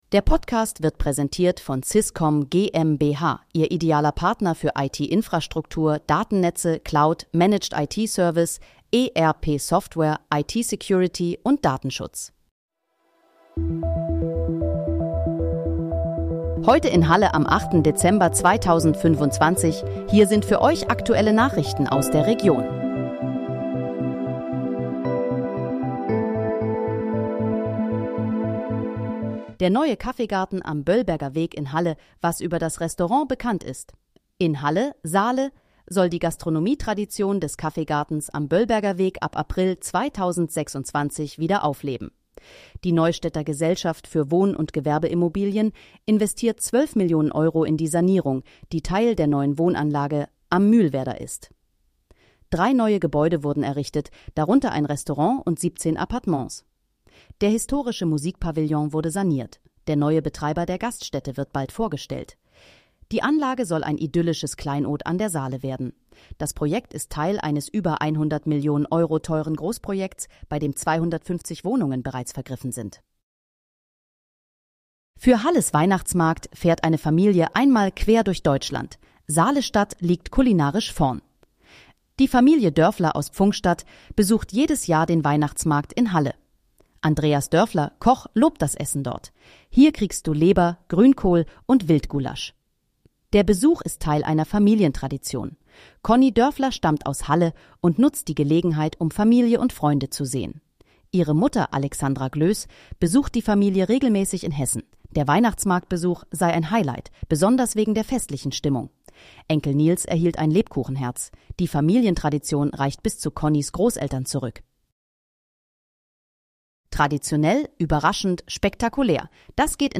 Heute in, Halle: Aktuelle Nachrichten vom 08.12.2025, erstellt mit KI-Unterstützung
Nachrichten